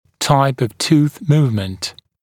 [taɪp əv tuːθ ‘muːvmənt][тайп ов ту:с ‘му:вмэнт]тип перемещения зуба (зубов)